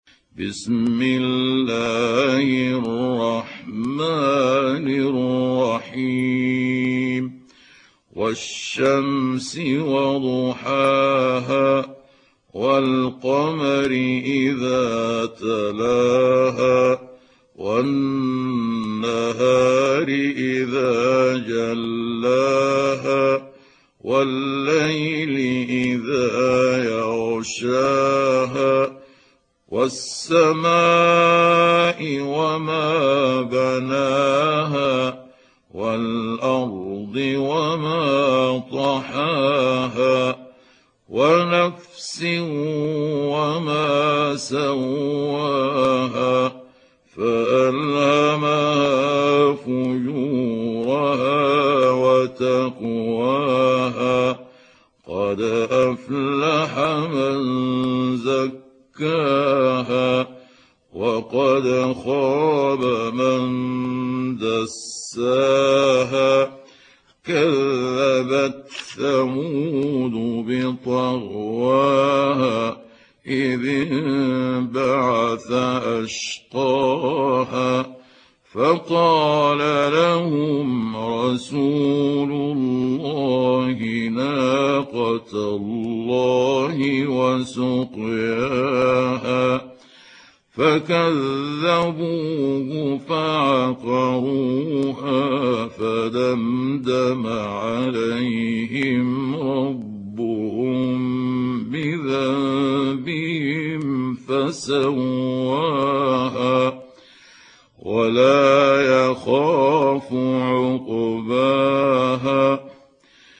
دانلود سوره الشمس mp3 محمود عبد الحكم روایت حفص از عاصم, قرآن را دانلود کنید و گوش کن mp3 ، لینک مستقیم کامل